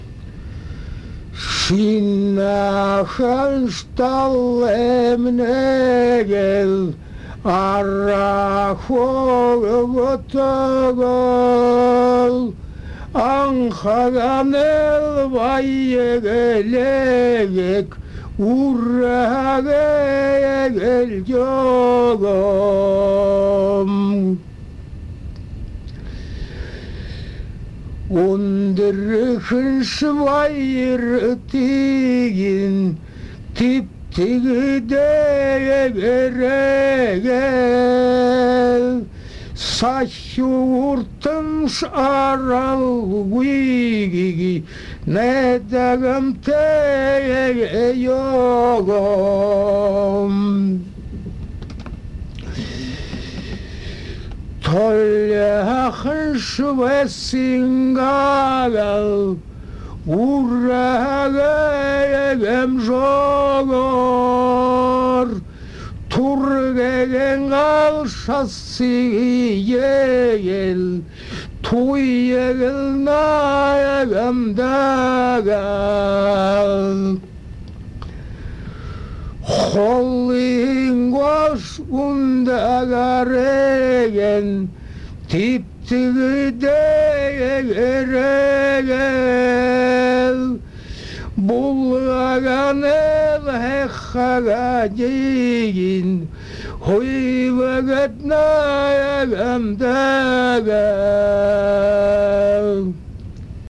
01«Ангуушадай дуун» («охотничья песня»